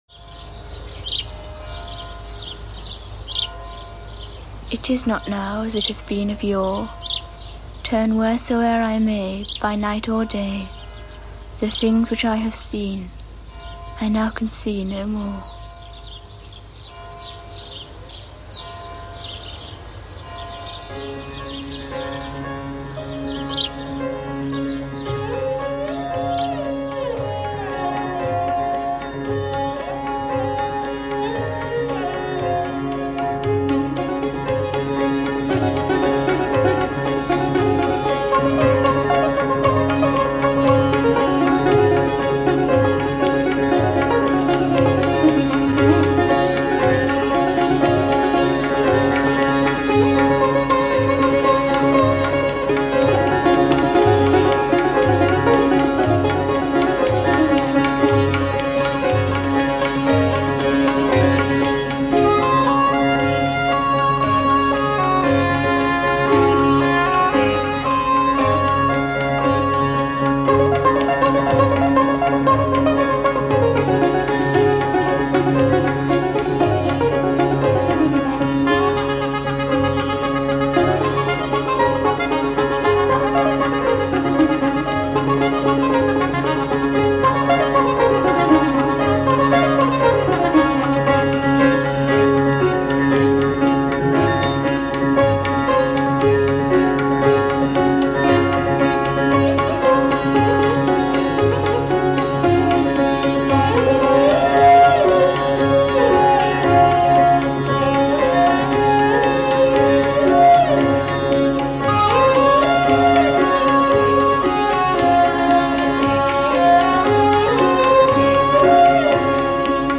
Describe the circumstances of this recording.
The music for the end credits